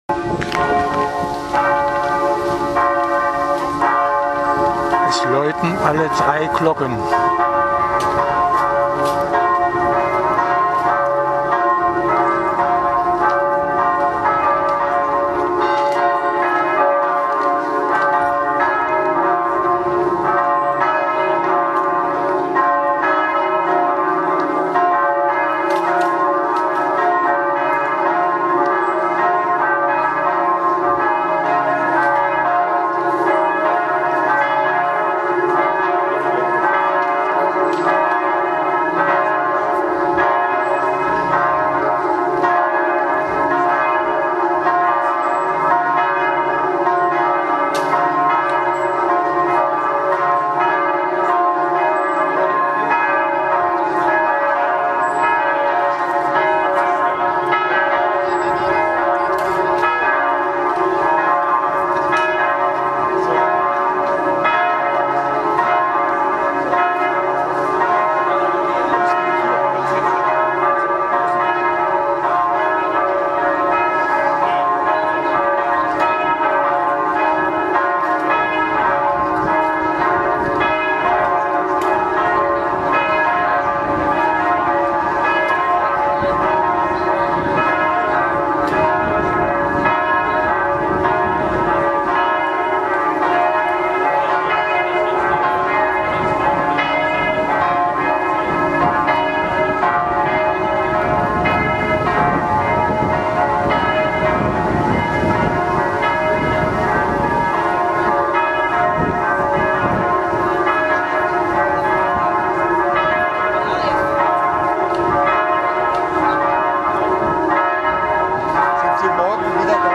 Glocken erklingen von der Trinitatiskirche : 21.07.2020, 21:37 Uhr
21.07.2020, 21:37 Uhr : Am Montag konnten die Zuhörer die Glockentöne von 3 Glocken hören.
Glockenklang
Am Anfang ertönten die 3 Glocken einzeln. Danach erklangen alle Glocken gemeinsam.